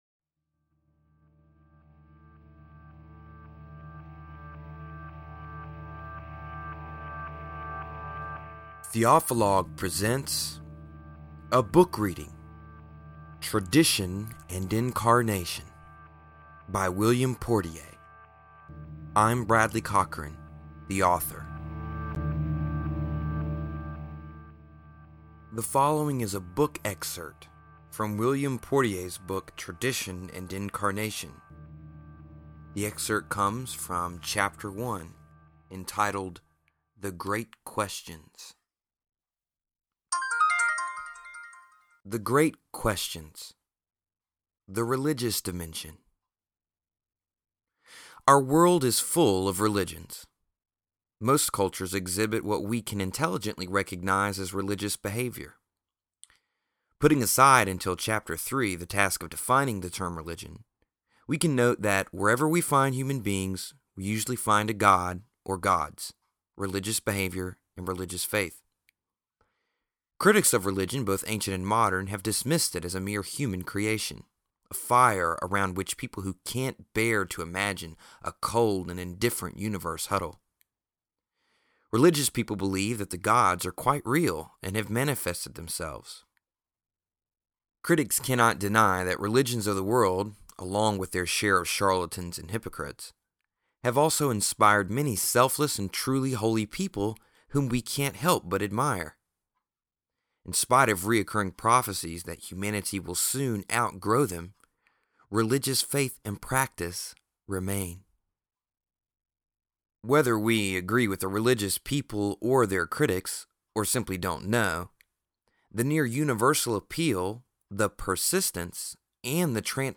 The following audio is a book reading about “The Great Questions” from William Portier’s Tradition and Incarnation: Foundations of Christian Theology (Mahwah, New Jersey: Paulist Press 1994), 9-16.